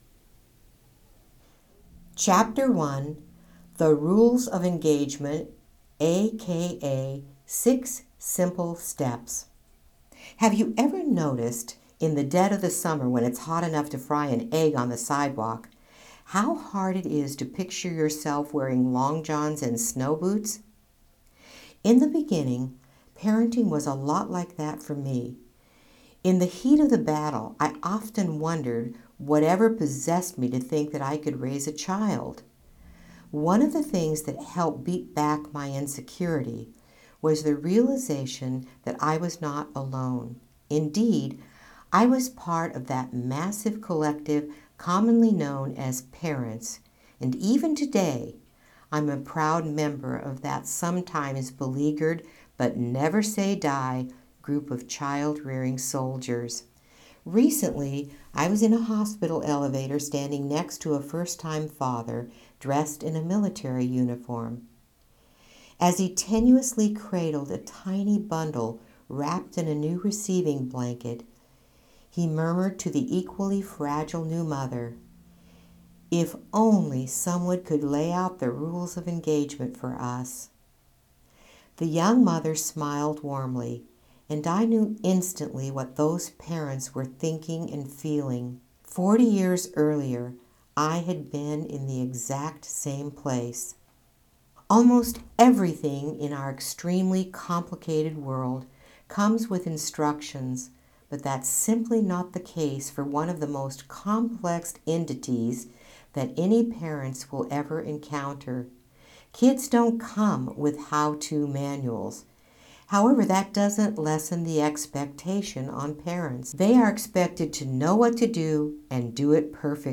AUDIO BOOK ABOUT PARENTING SUCCESS FOR PARENTS